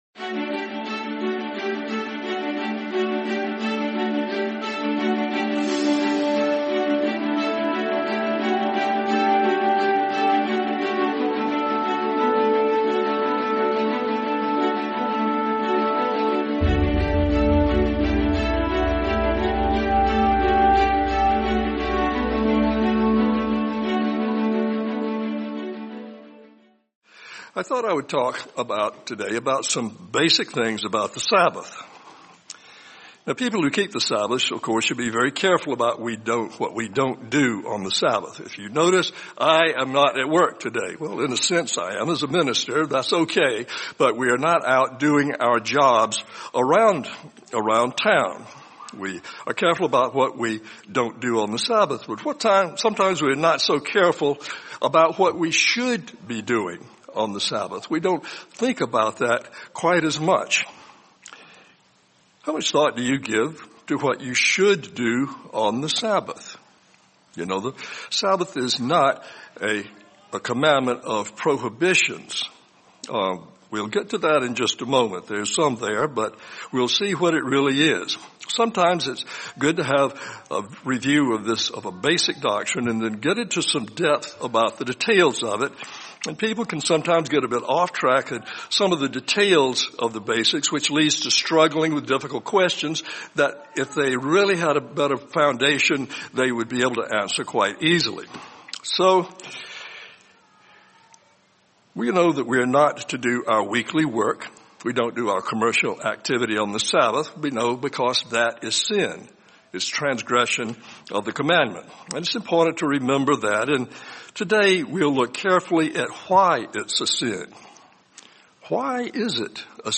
| Sermon | LCG Members